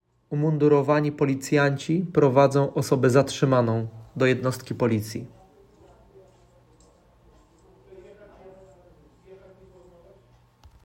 Opis nagrania: Nagranie głosowe do załączonego filmu.